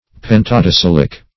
pentadecylic - definition of pentadecylic - synonyms, pronunciation, spelling from Free Dictionary
Pentadecylic \Pen`ta*decyl"ic\
(p[e^]n`t[.a]*d[-e]*s[i^]l"[i^]k), a. [Penta- + decylic.]